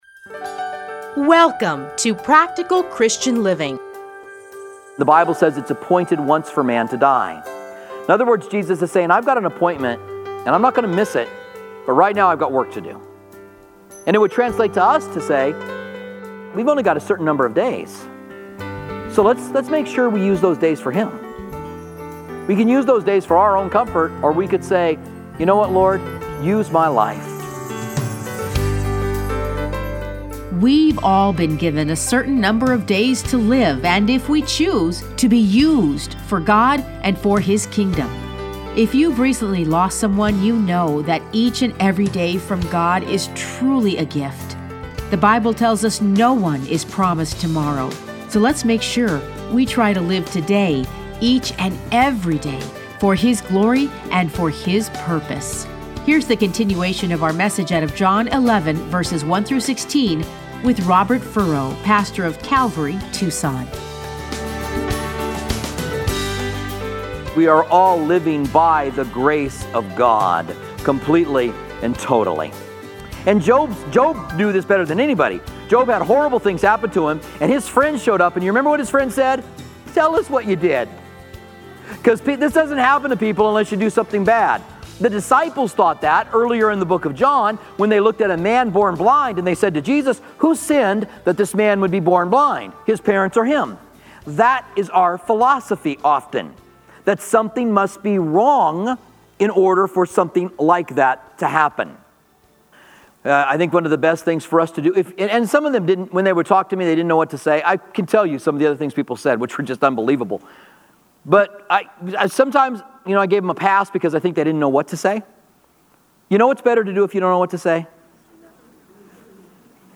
Listen to a teaching from John 11:1-16.